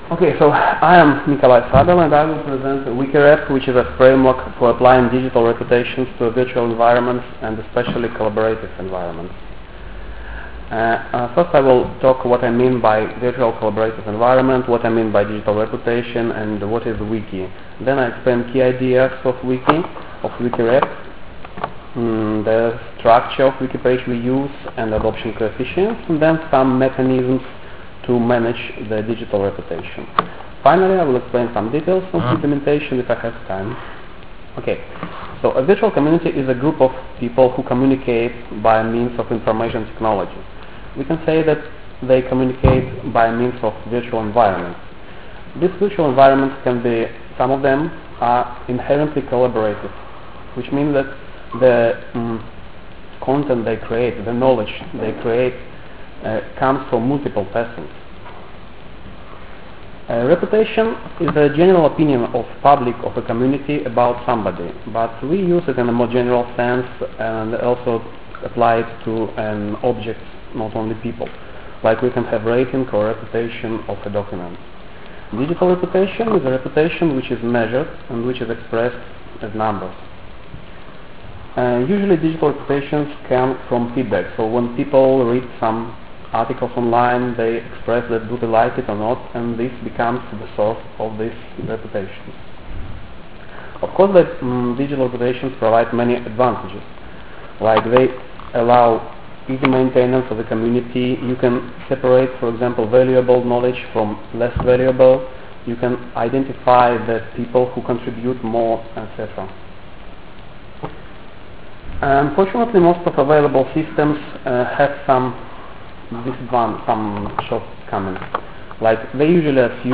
Congresso Annuale AICA2005 (Udine, 5-7 October, 2005) ( paper, zipped MS-Word doc (+bibtex) ) ( bibtex ) ( presentation slides, pdf ) ( audio record of the talk, ogg vorbis )